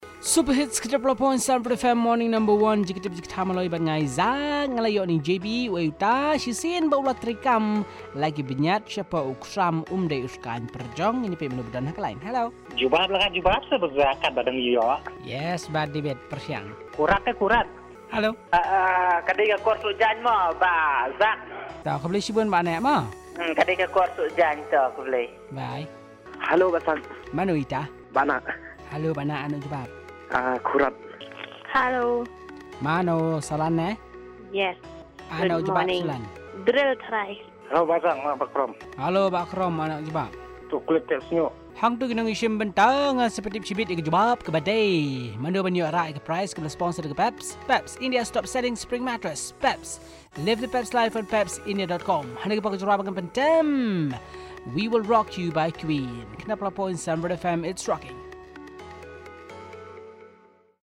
Callers with their answers